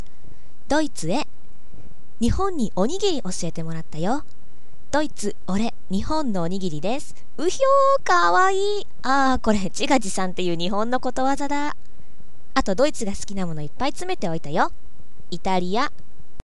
ちらほら増え始めてるボイスサイト様とすごくコンタクトを取りに行きたくてもぎゅもぎゅ（どんな）してるのですが、それすらも出来ないまま無常に時間が過ぎていきます…。寂しいので、本家様からセリフを拝借してちょっと録音してみた
イタリアボイス置いときますね。ドイツへの愛妻弁当に付いてたお手紙の朗読です。